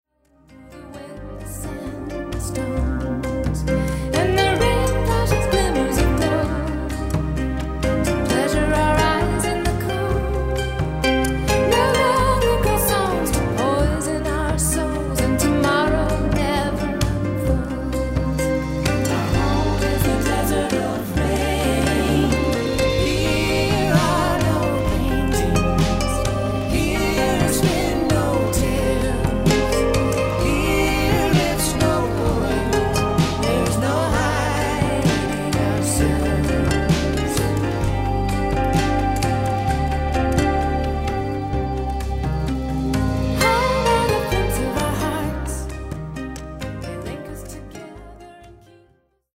Recorded & mixed at Powerplay Studios, Maur – Switzerland